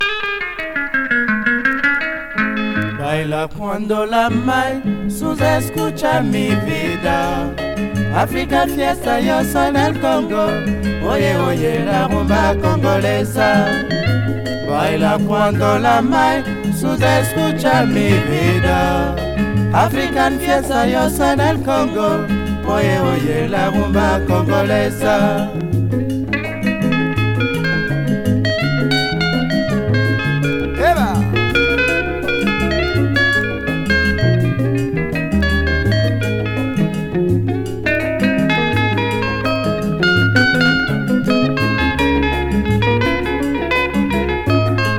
熱風とか地熱を感じるかのような心地良さと、独特の浮遊感を錯覚しそうな素晴らしさ。